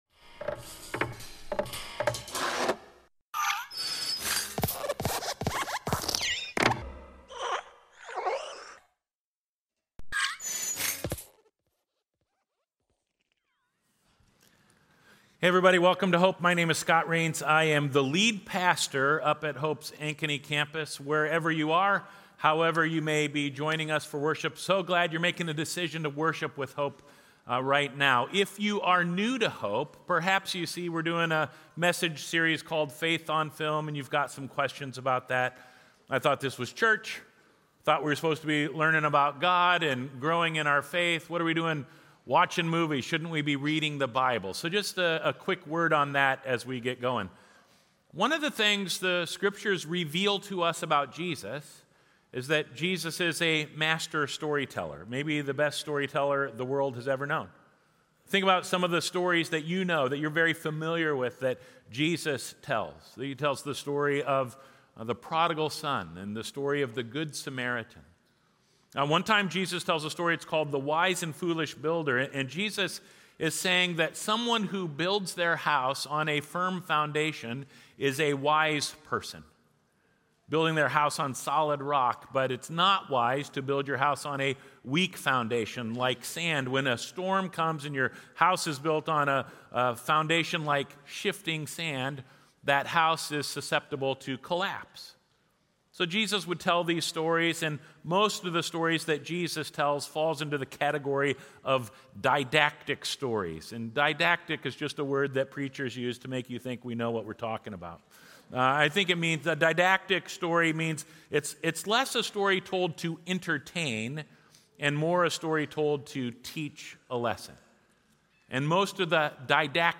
preaches Sunday, October 27, 2024, at Lutheran Church of Hope in West Des Moines, Iowa.